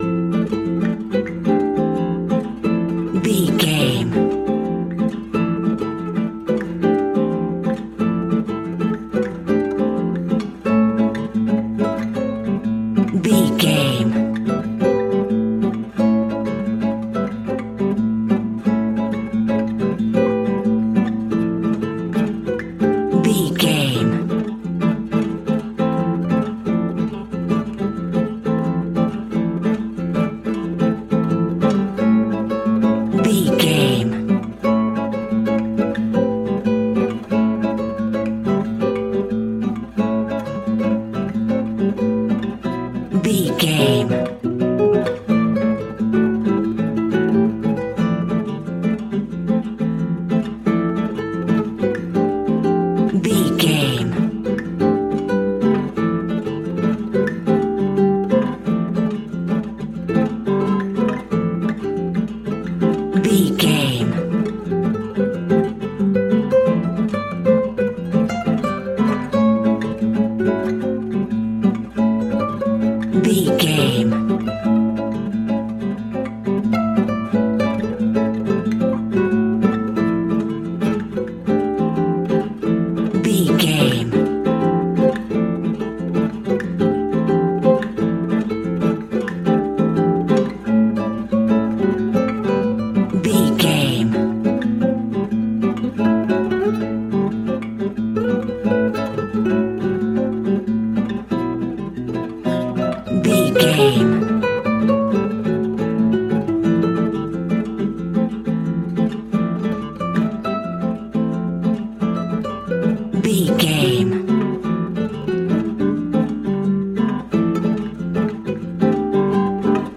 Ionian/Major
maracas
percussion spanish guitar